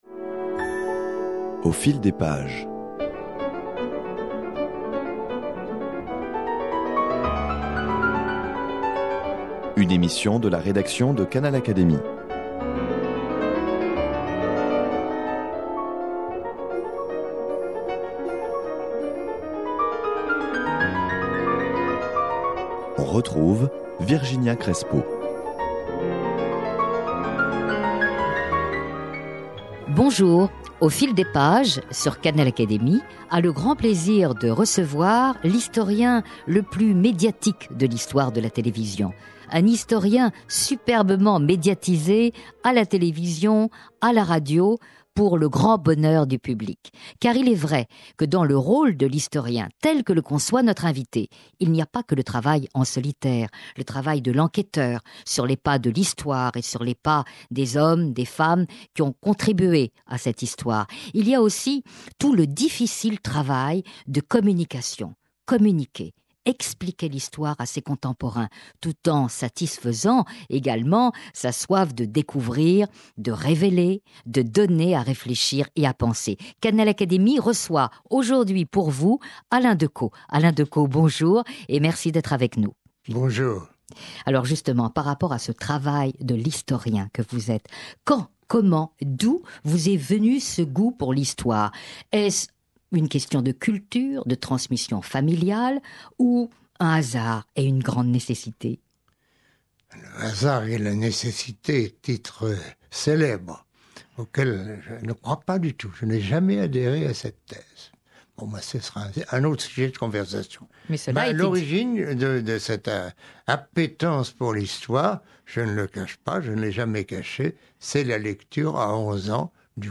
Il se confie ici sur ce ton personnel, chaleureux, combattif qui le rend si passionnant à écouter !